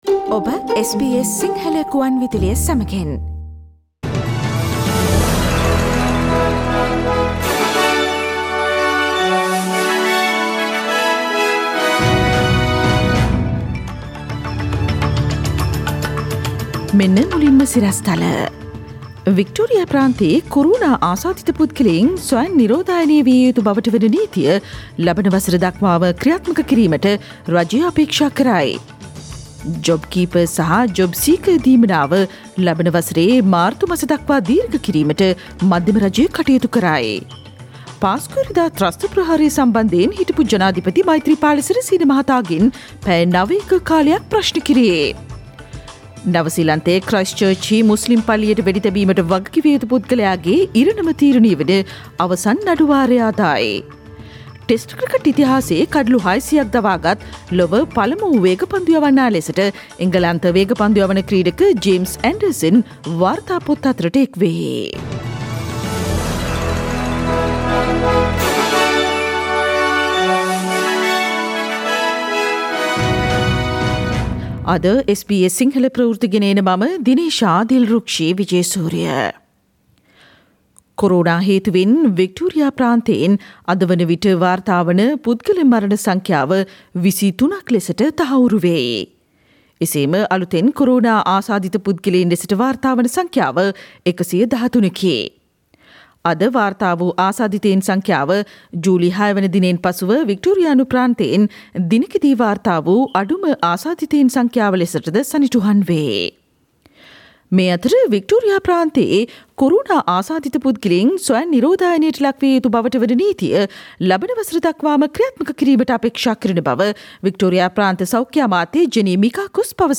Daily News bulletin of SBS Sinhala Service: Thursday 27 August 2020
Today’s news bulletin of SBS Sinhala radio – Thursday 6 August 2020.